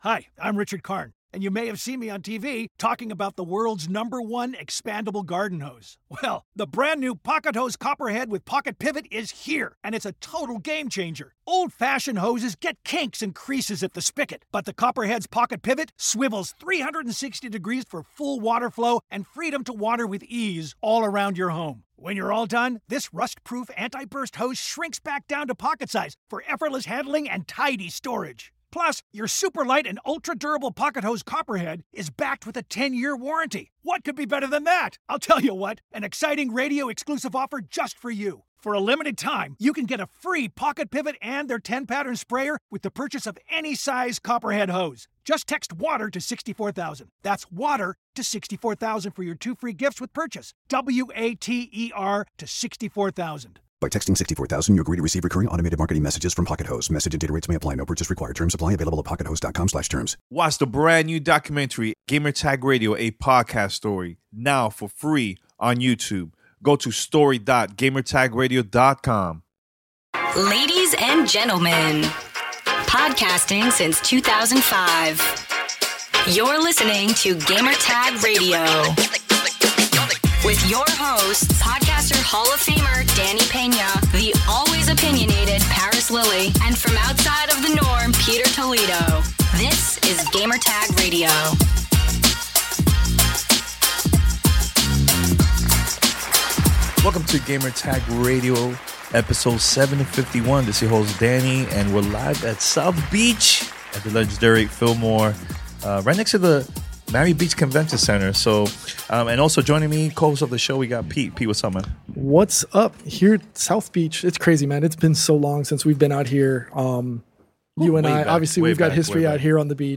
League of Legends NALCS Spring Finals 2018 Coverage Day 2 live at South Beach in Miami, FL.
Roundtable discussion about the event, red carpet interviews with Team Liquid and 100 Thieves.
Winners press conference NA LCS Spring Finals 2018.